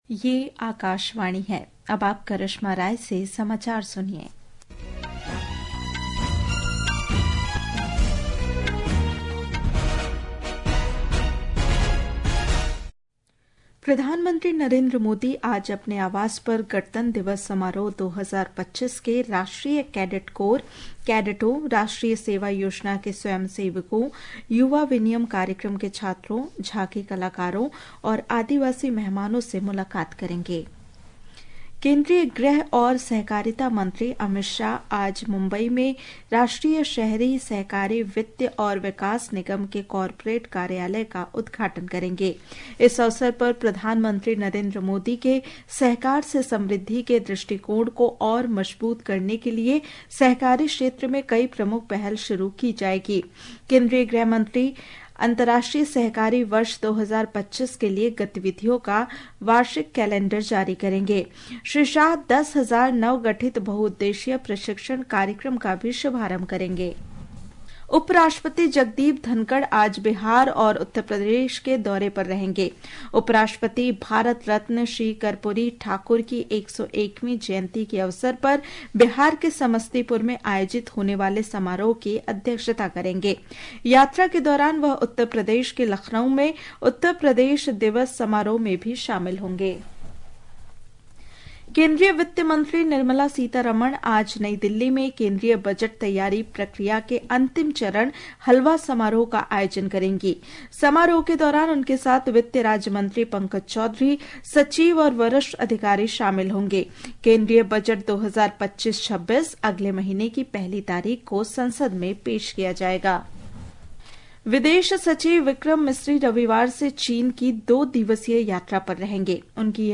قومی بلیٹنز
प्रति घंटा समाचार